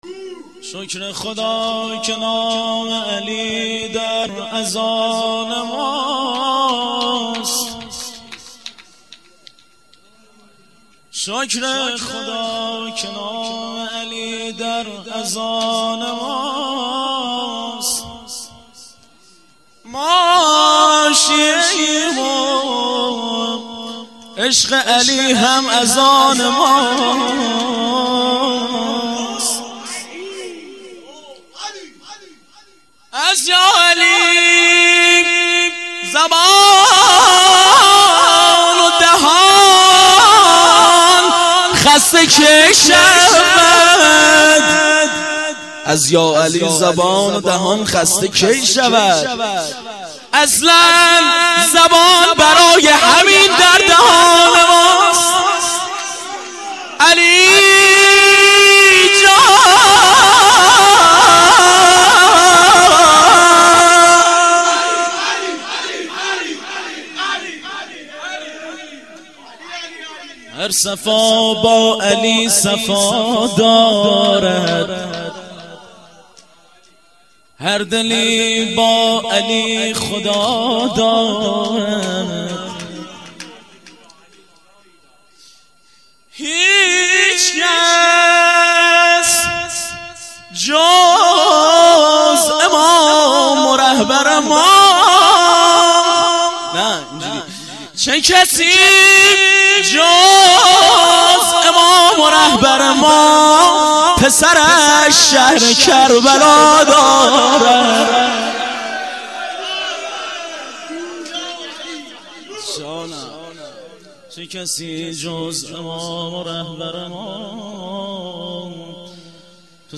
مدح- شکر خدا که نام علی